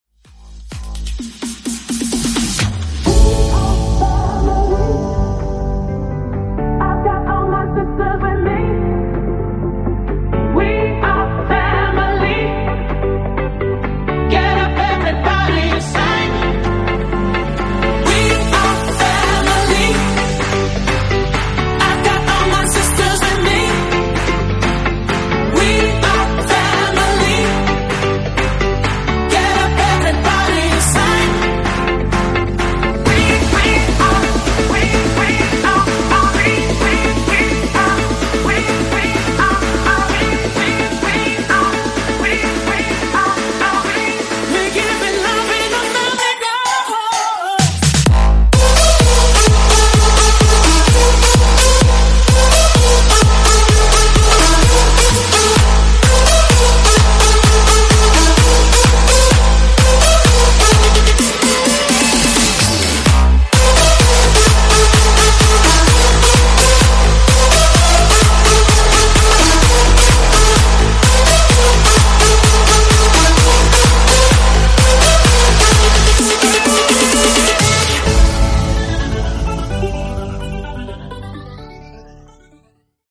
Genres: DANCE , EDM , RE-DRUM
Clean BPM: 128 Time